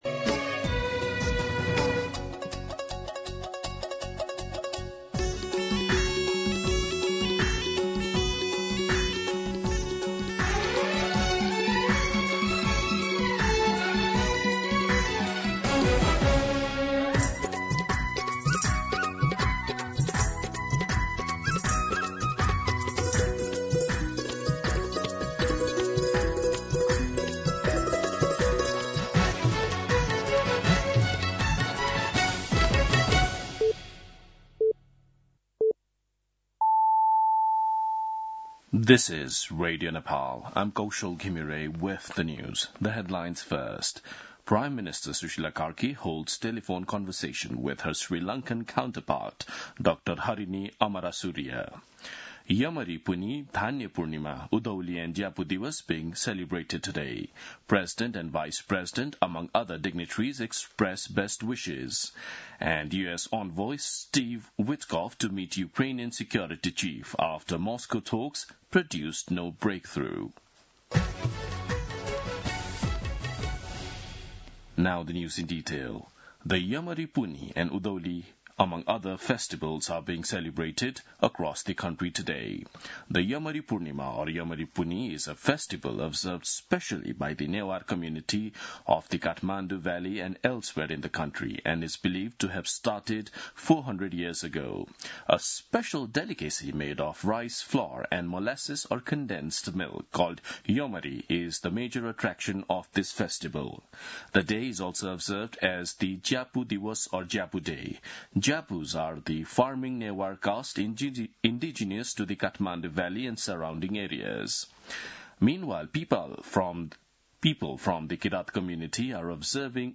दिउँसो २ बजेको अङ्ग्रेजी समाचार : १८ मंसिर , २०८२
2-pm-English-News.mp3